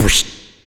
SI2 PSCHUIT.wav